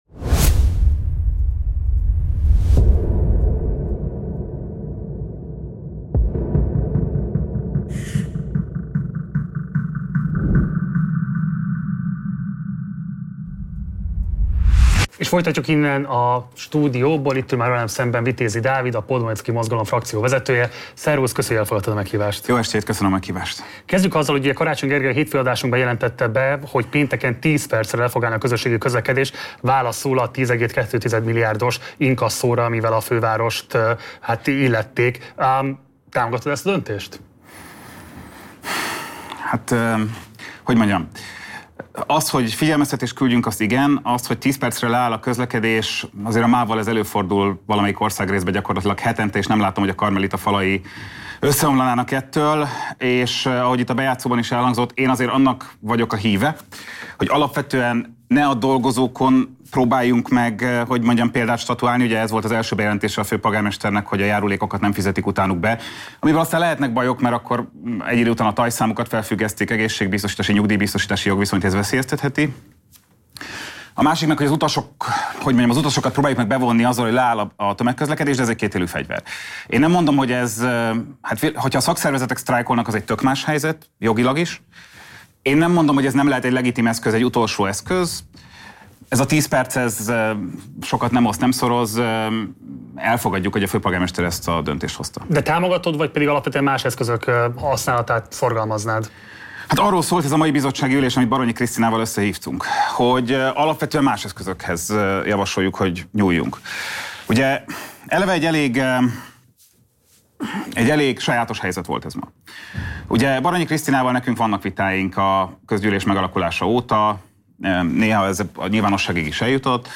Kiléphet-e a KDNP a szatelitpárt-szerepből, és ha igen, milyen párt lenne, miben különbözne a Fidesztől? Interjú Gaal Gergellyel.–FOLYTASD KÖZÖS TÖRTÉNETÜNKET!A Partizán függetlenségét és fennmaradását mindig is a közösség biztosította – ezt összefogást akarja ellehetetleníteni a kormány a cenzúratörvénnyel.